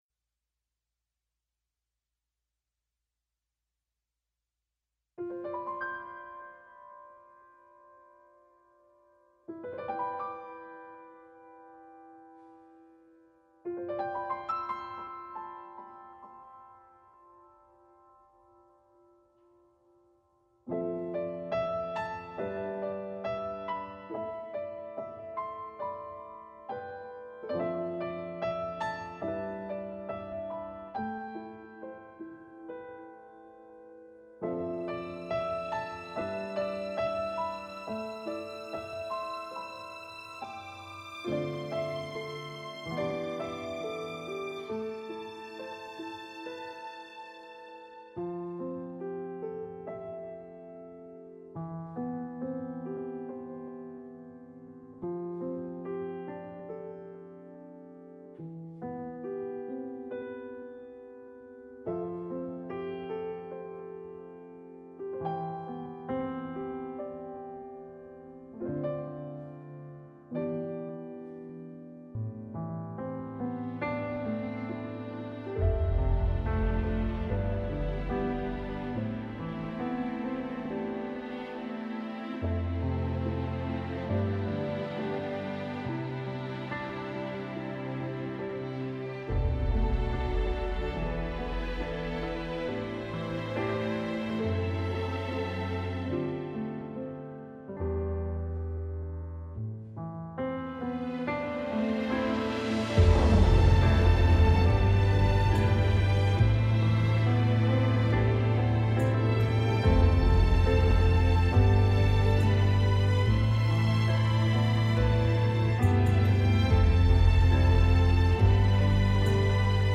伴奏